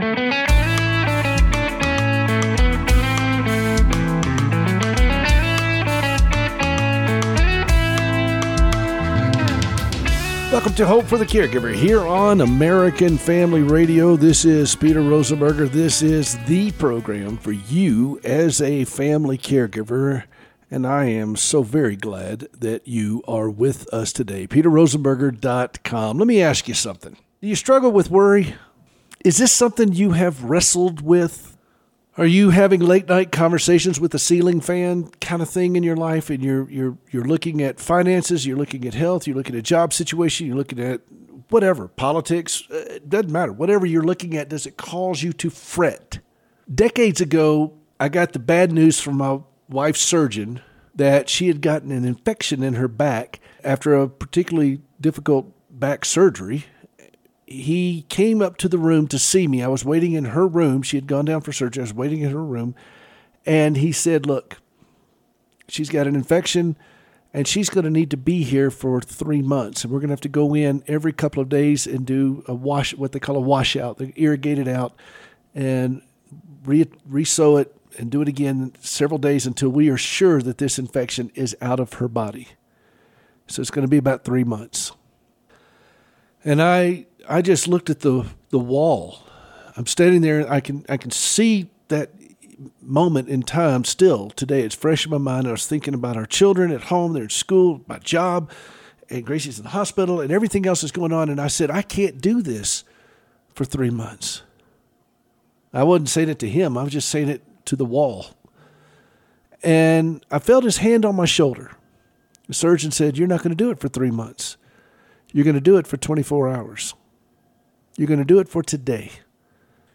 LIVE on Saturday mornings at 7:00AM.